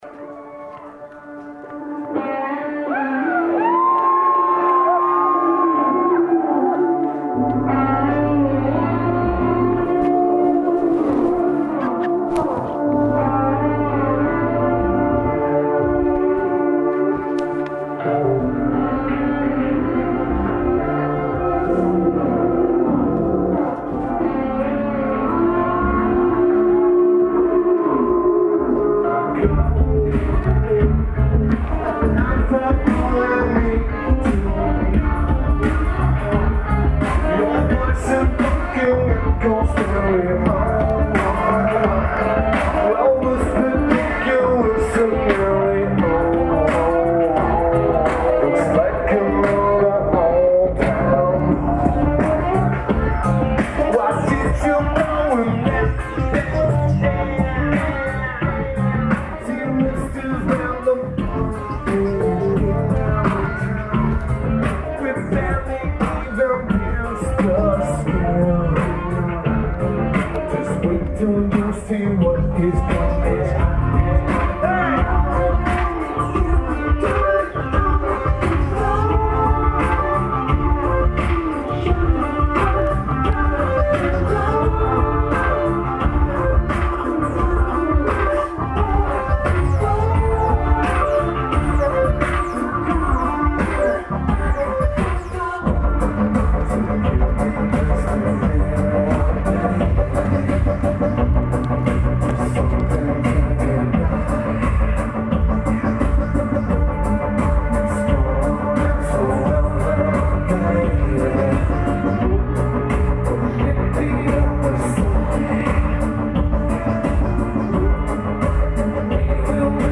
Studio Coast
Lineage: Audio - AUD (Olympus Voice-Trek V-75)